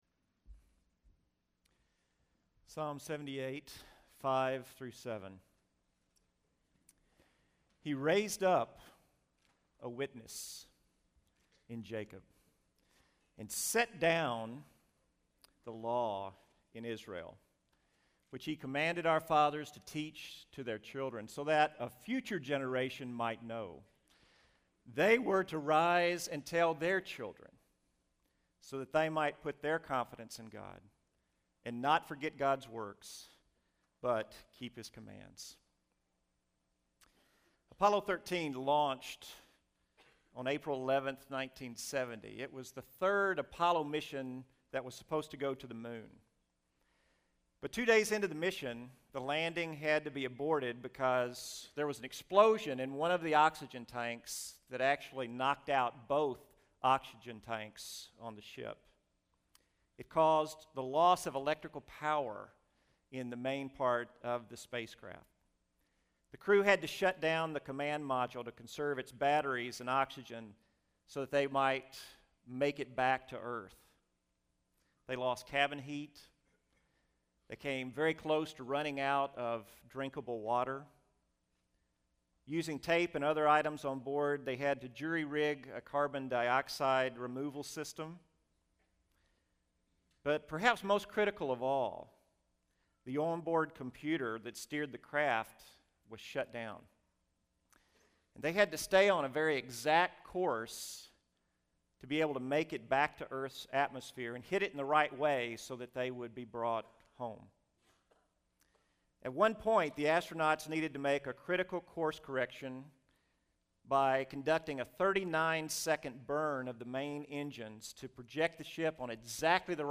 Homecoming Chapel